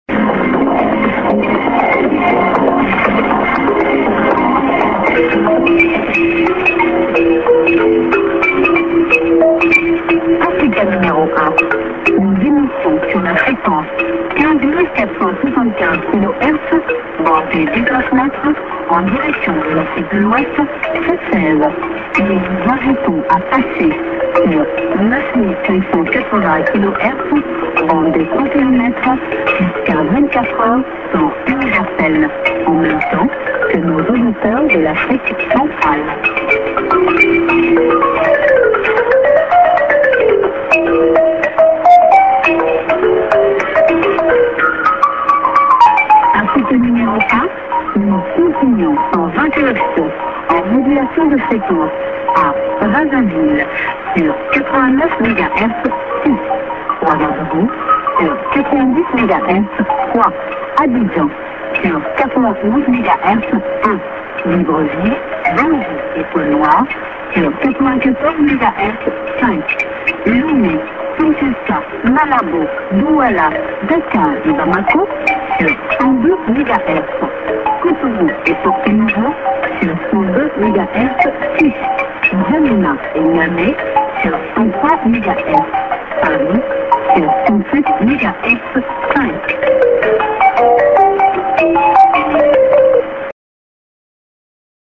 End ->ID+SKJ(women)->